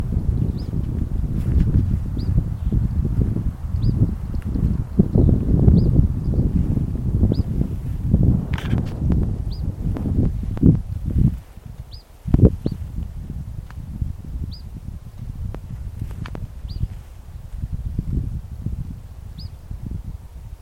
Vermilion Flycatcher (Pyrocephalus rubinus)
Un macho vocalizando un contacto.
Sex: Male
Location or protected area: Saavedra - Partido de Saavedra
Condition: Wild
Churrinche.mp3